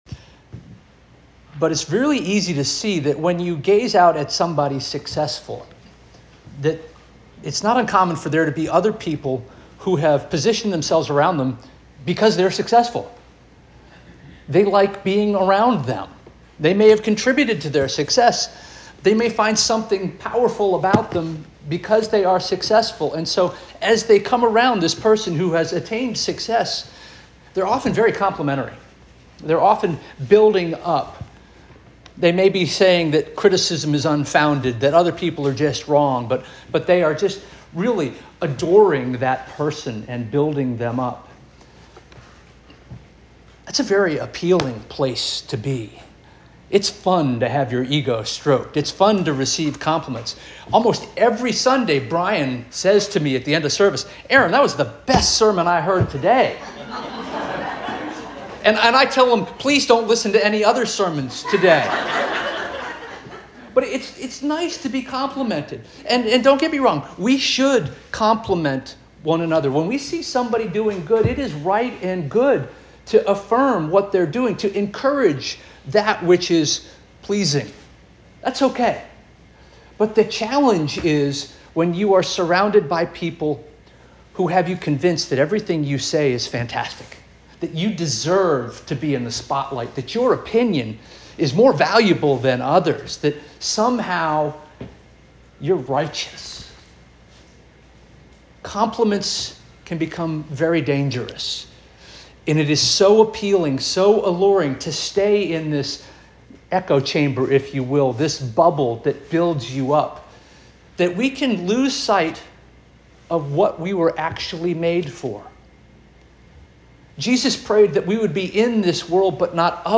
October 5 2025 Sermon - First Union African Baptist Church